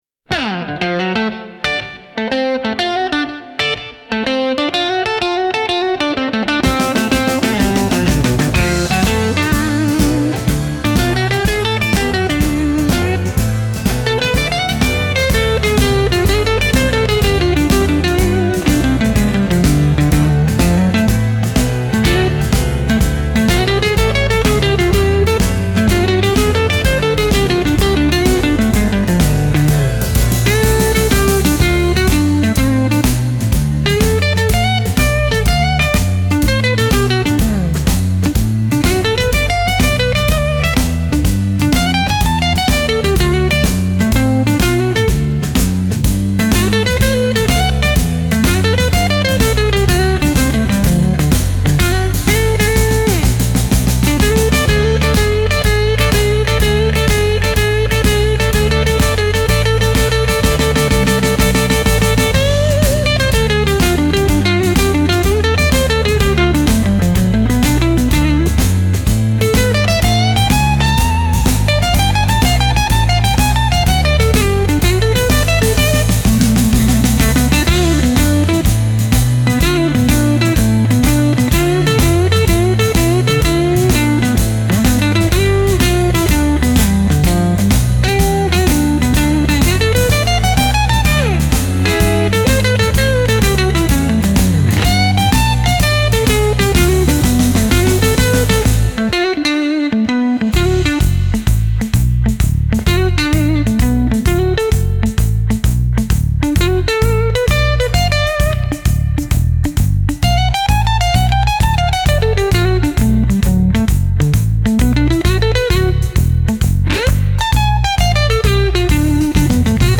Instrumental - RLMradio Dot XYZ - 2.28.mp3